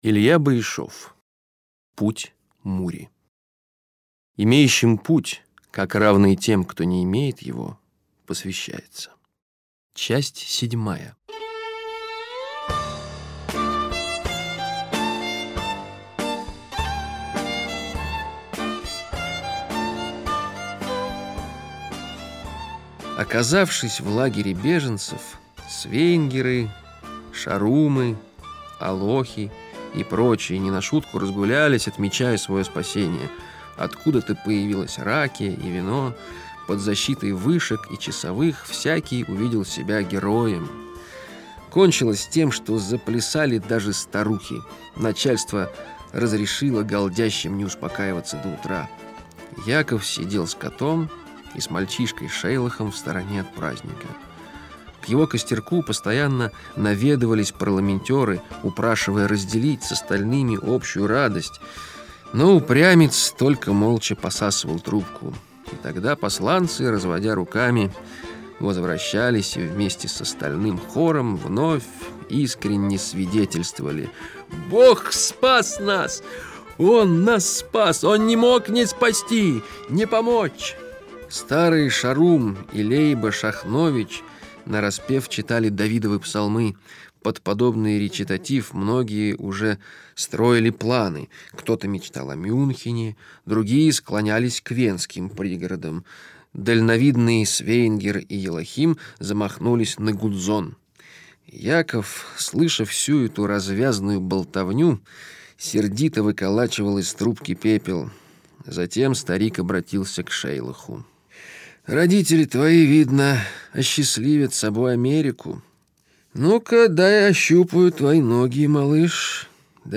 Все программы - Литературные чтения